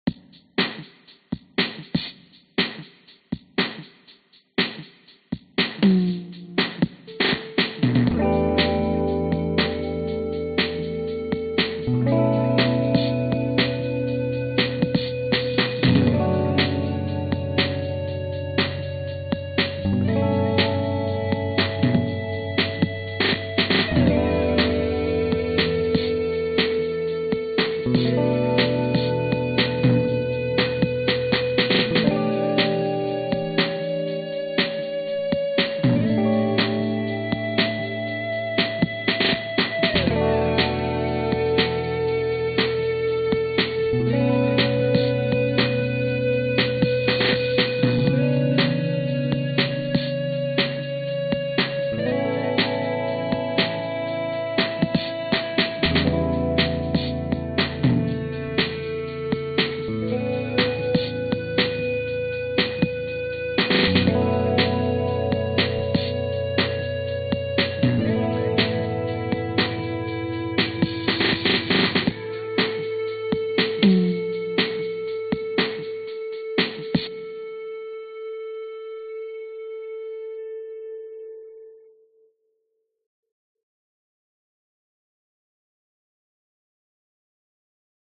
ebow guitar » ebow new 9 13 lg
描述：An almost flutelike guitar sample made using the amazing Ebow gizmo with my Epiphone Les Paul guitar through a Marshall amp. Some reverb added.
标签： ambience atmosphere ebow electronic guitar music processed
声道立体声